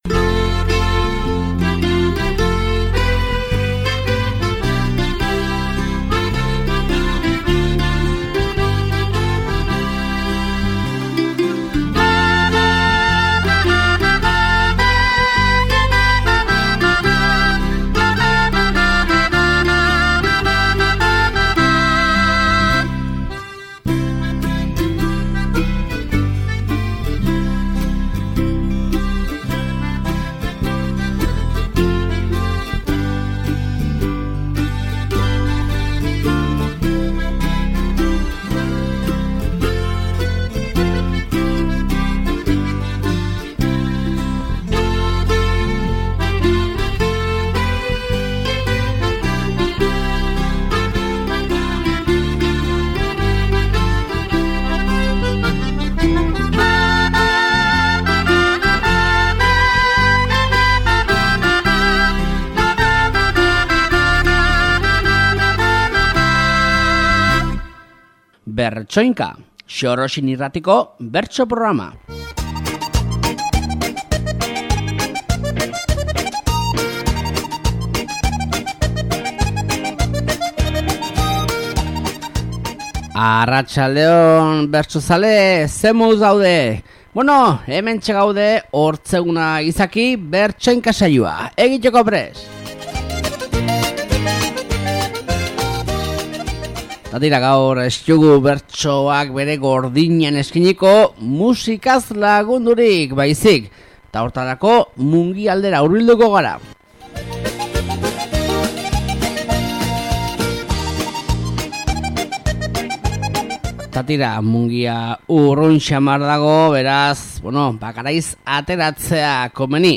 Xorroxin irratiko bertsolaritzari buruzko irratsaioa.
BERTSOINKA-Tafalla aldeko uholdeek kalteturikoei babesa adierazteko bertso saioa